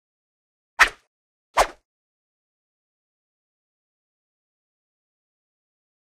Sword: Swish ( 2x ); Two Swishes In Quick Sequence. The First One Is Faster And Higher Pitched Than The Second. Close Perspective. Whoosh.